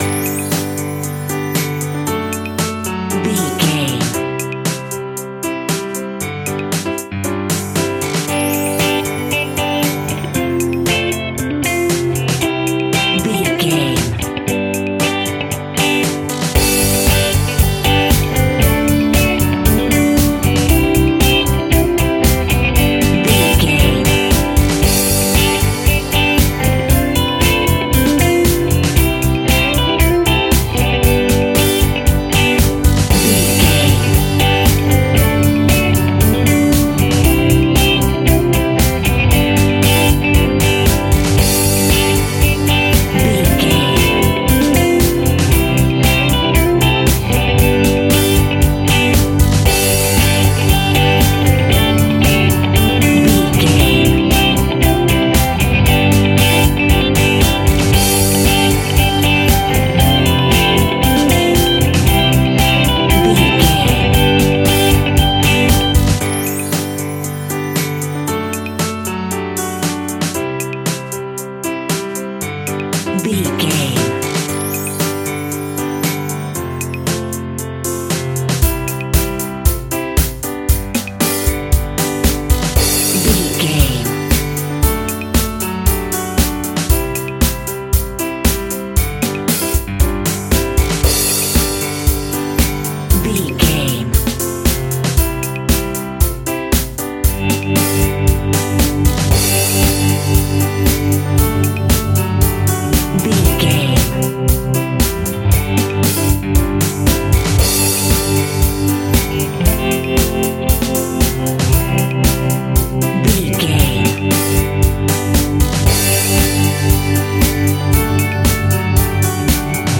Ionian/Major
pop rock
indie pop
fun
energetic
uplifting
cheesy
drums
bass guitar
electric guitar
synthesizers